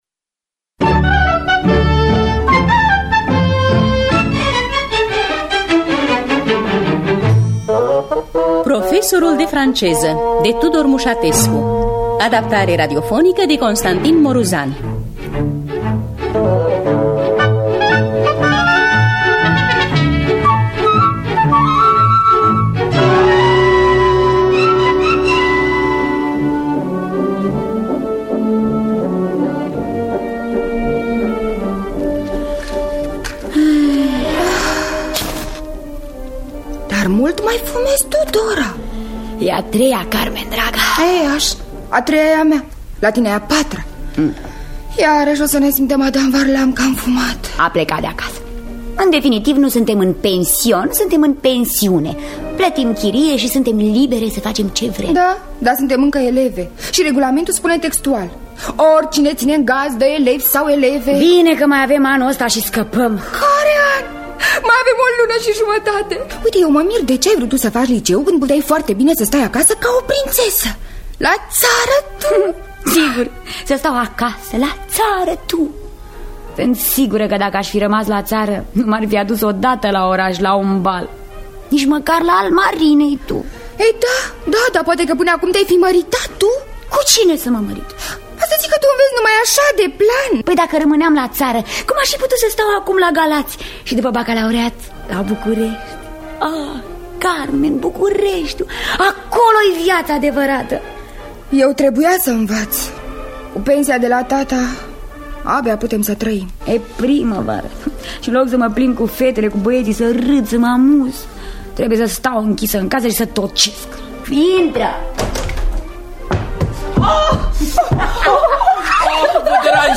“Profesorul de franceză” de Tudor Muşatescu – Teatru Radiofonic Online